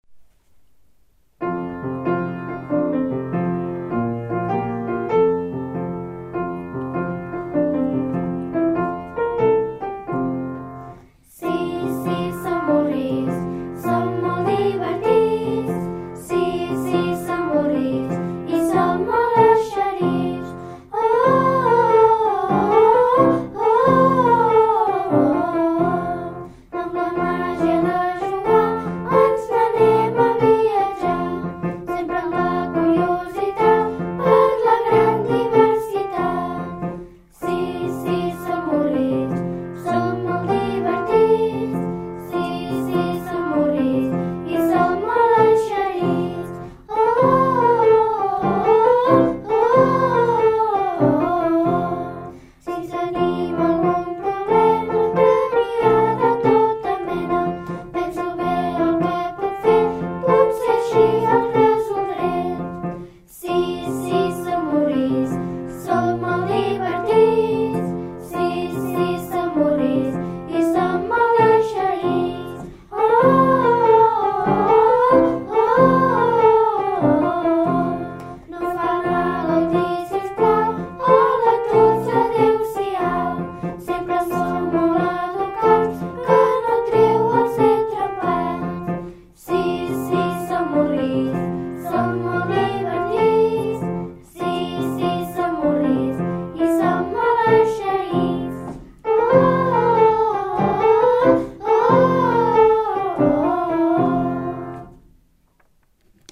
TOTS PLEGATS VAM CANTAR LA SEVA CANÇÓ.